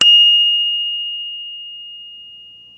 Kék figurás csengő, elsősorban gyermek kerékpárokra, 22,2 mm-es bilinccsel
45 mm átmérőjű csengő spirálrugós pengetővel, hogy a gyerekeknek könnyű legyen megszólaltatni
Jópofa kis csengő, extrém hangerővel!